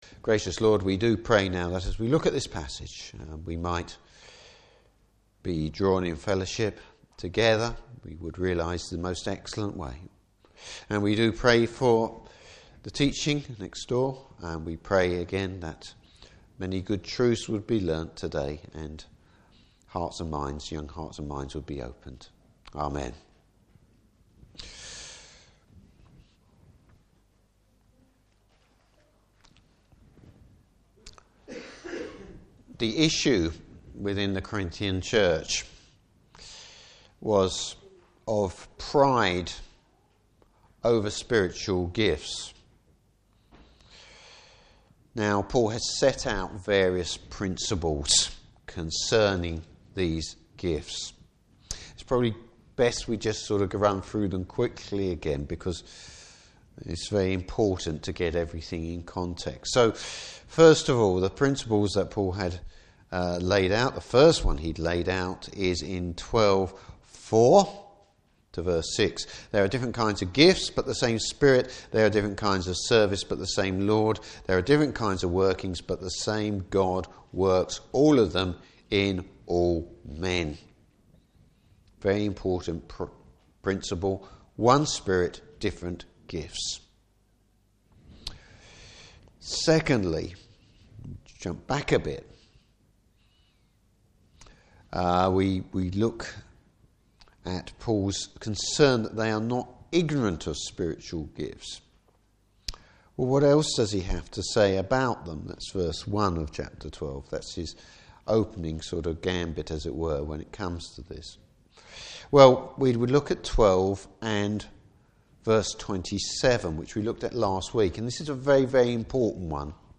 Service Type: Morning Service How love is to be the foundation for all we say and do.